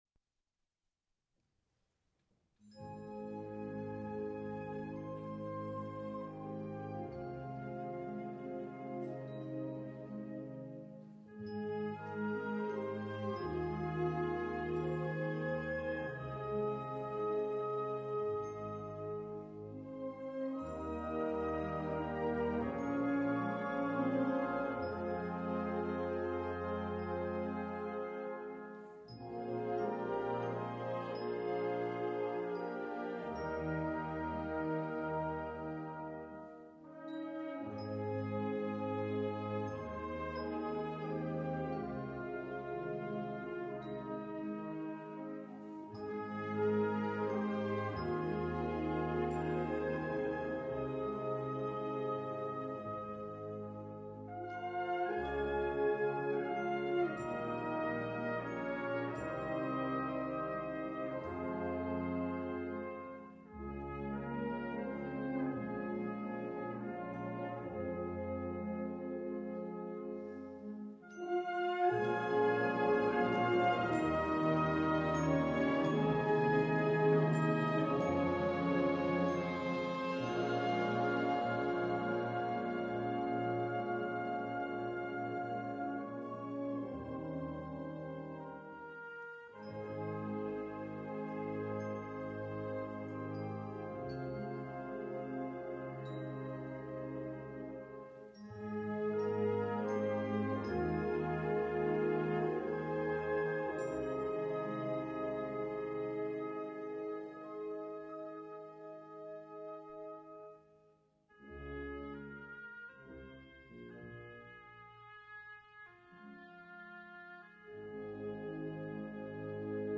Concert Band: A-flat Major (original key)
Concert Band